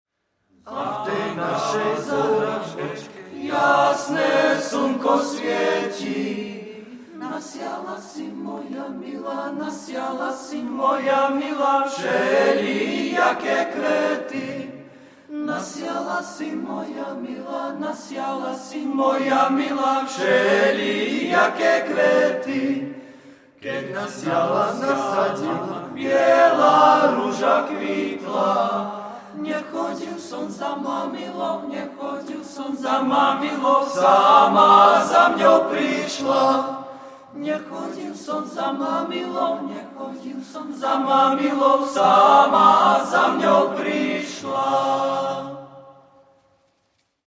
Koncert diel Viliama Figuša Bystrého
Zbor Viliama Figuša Bystrého